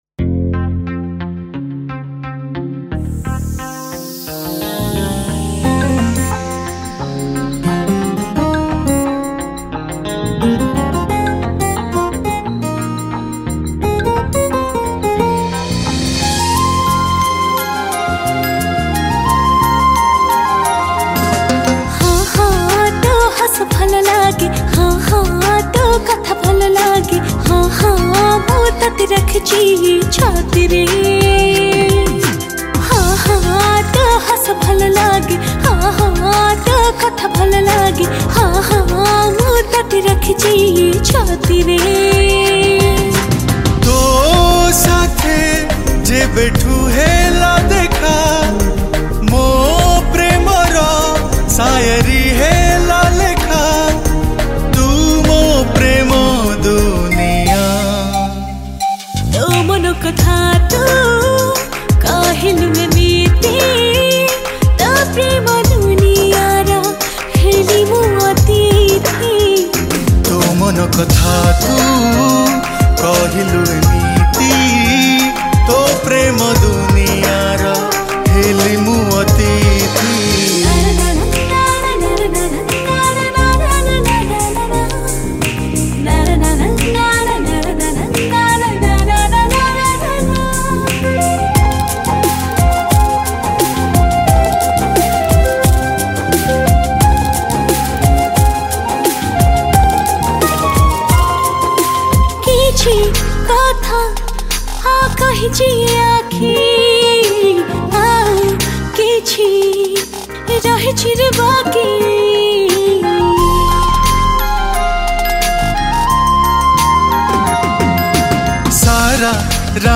Odia New Romatic Song